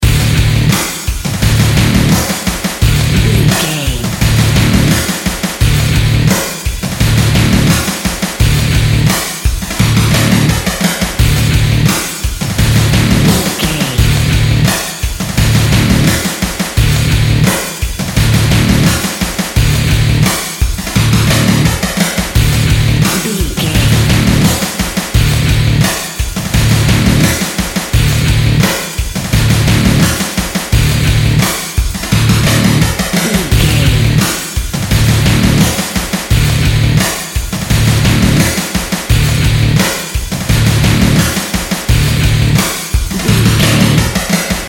Aeolian/Minor
D
aggressive
powerful
dark
futuristic
industrial
drums
electric guitar
Drum and bass
break beat
electronic
sub bass
synth
darkcore
synth drums
synth leads
synth bass